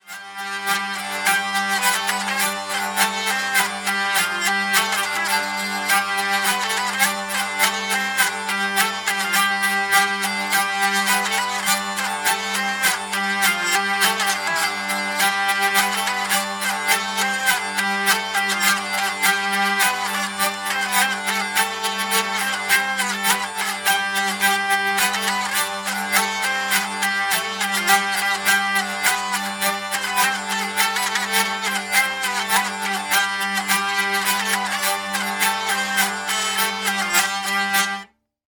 Gurdy.mp3